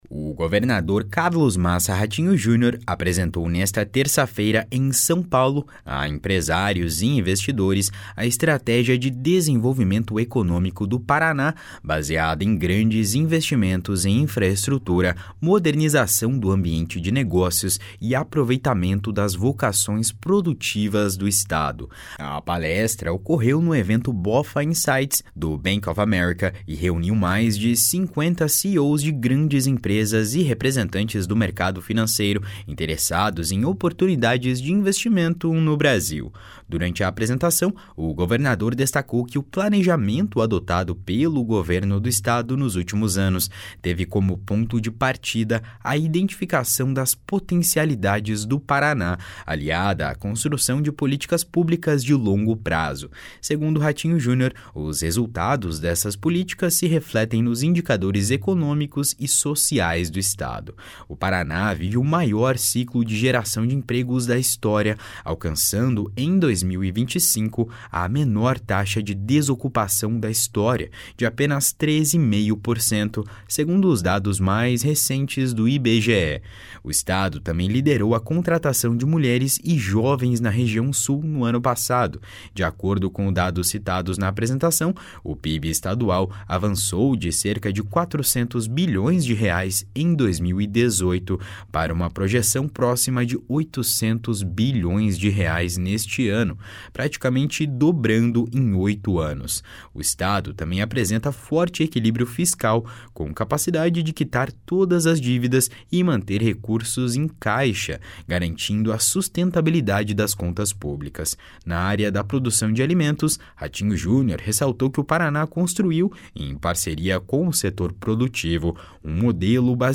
A palestra ocorreu no evento BofA Insights: Road to 2026, do Bank of America, e reuniu mais de 50 CEOs de grandes empresas e representantes do mercado financeiro interessados em oportunidades de investimento no Brasil. Durante a apresentação, o governador destacou que o planejamento adotado pelo Governo do Estado nos últimos anos teve como ponto de partida a identificação das potencialidades do Paraná, aliada à construção de políticas públicas de longo prazo.